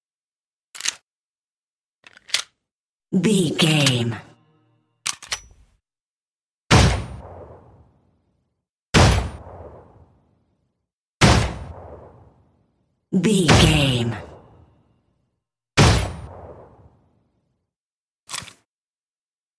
Pistol Equip, Clip eject, Insert, Reload, Firing and Unequip 04 | VGAME
Filled with 10 sounds(44/16 wav.) of Pistol Equip, clip eject, Insert, Reload, Firing(Five single shots) and Unequip.
Sound Effects
Adobe Audition, Zoom h4
handgun